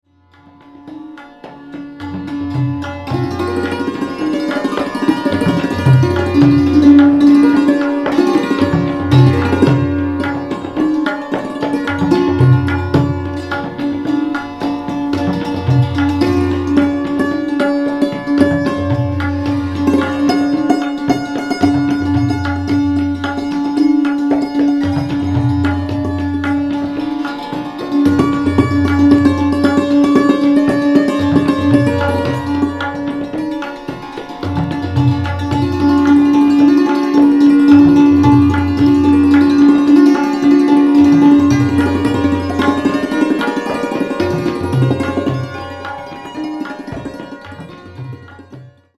台形の箱型共鳴胴に張った鋼鉄製の弦を撥で打って奏でるペルシャ由来の打弦楽器サントゥール。
インド　即興